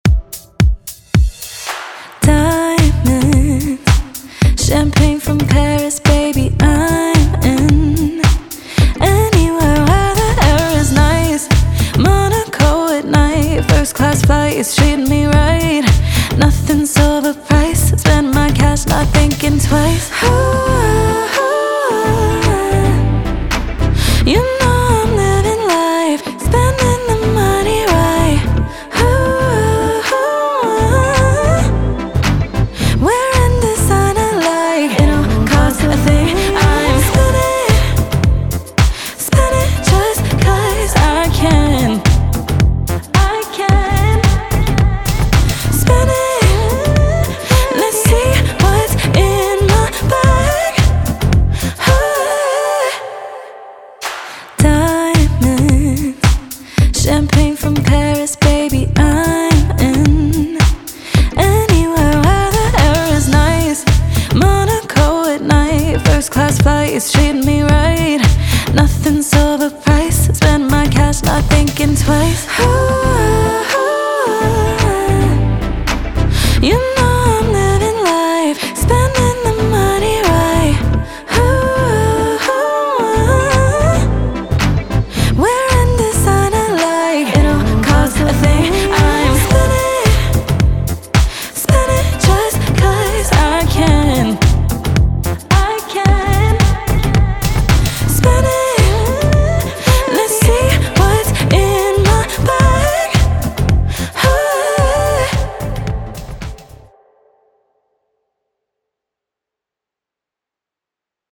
Pop
A Minor